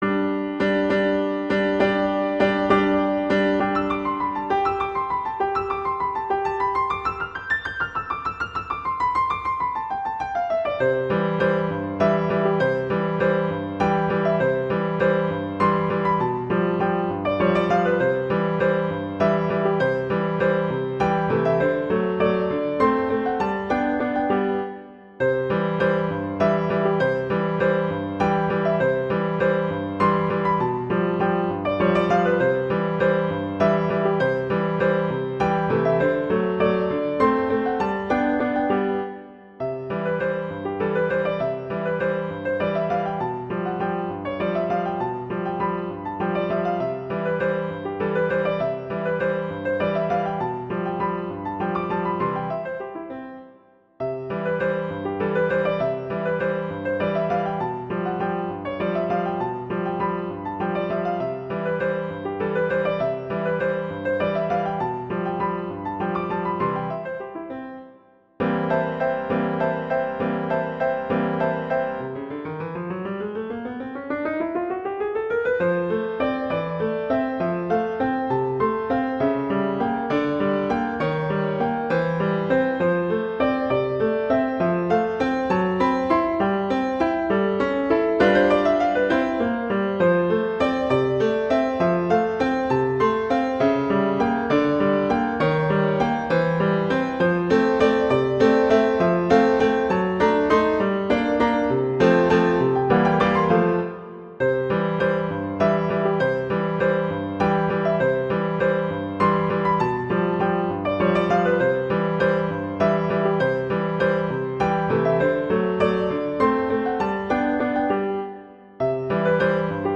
Instrumentation: piano solo
classical, wedding, traditional, french, festival, love
C major
♩=200 BPM